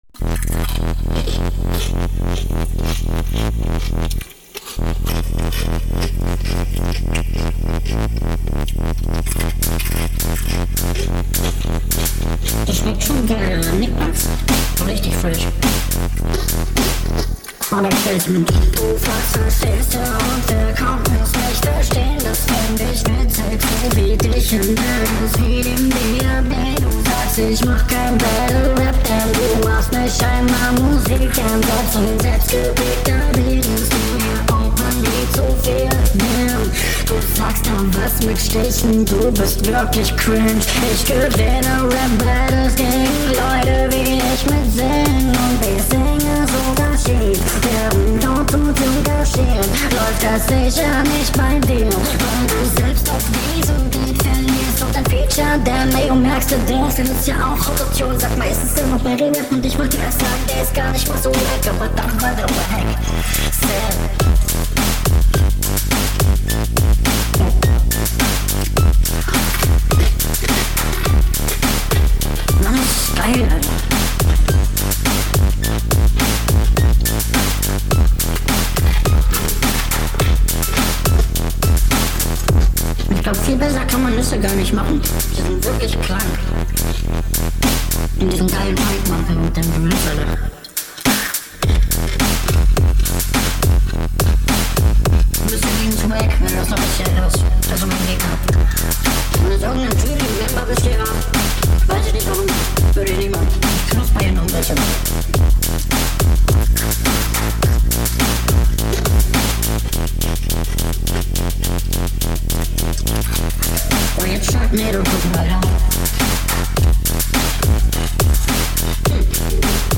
Der Text ist leider nicht wirklich zu verstehen.